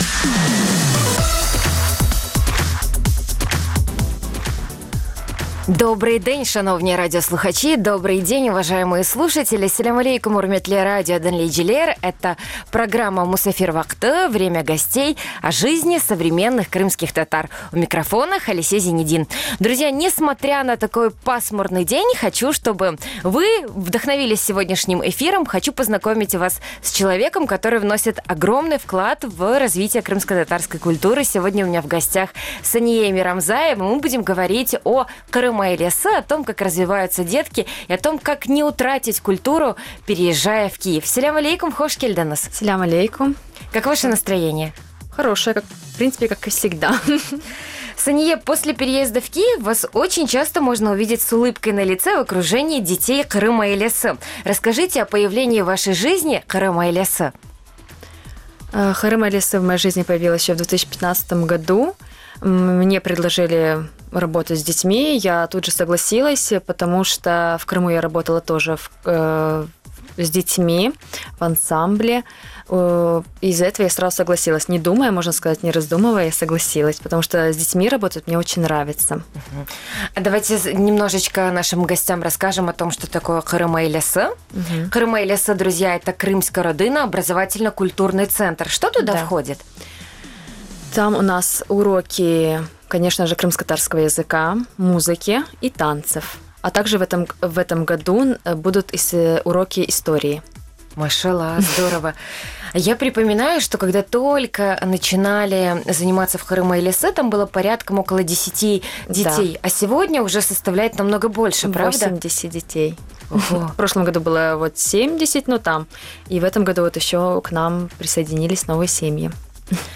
Эфир можно слушать Крыму в эфире Радио Крым.Реалии (105.9 FM), а также на сайте Крым.Реалии.